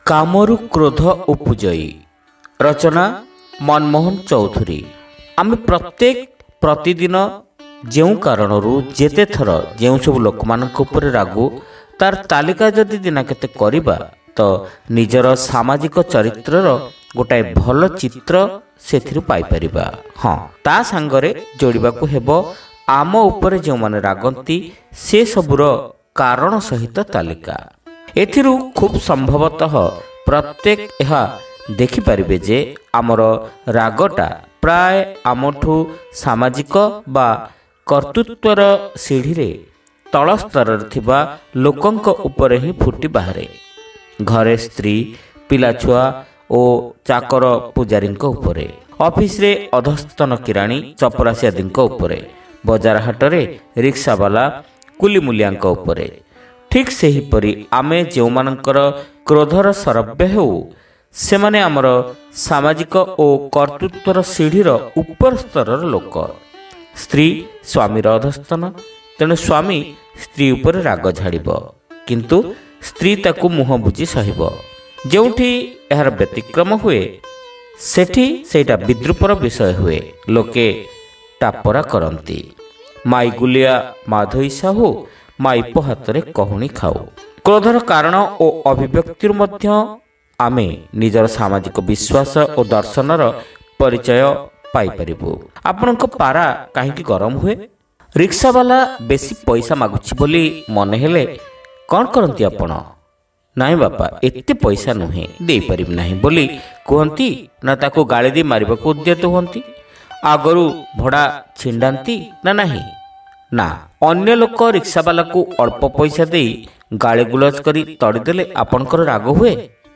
Audio Story : Kamaru Krodha Upujai